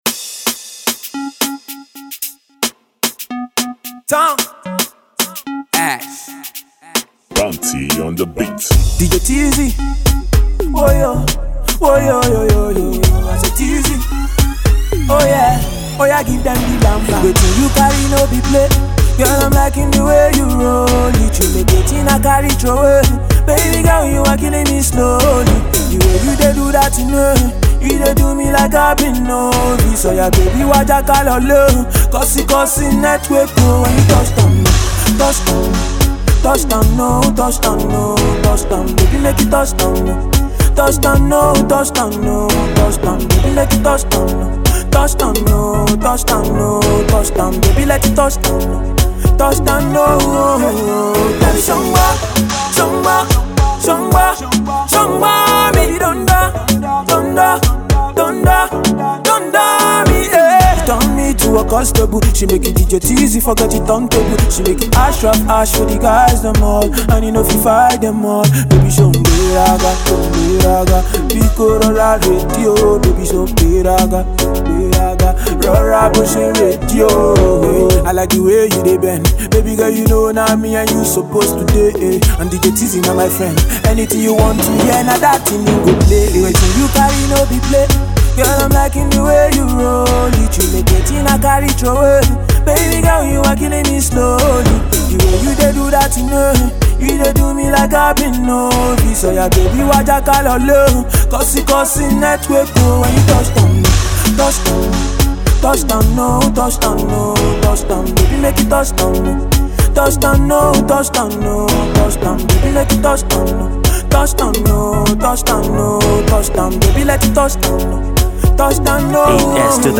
Alternative Pop
a singer/rapper
a dancehall track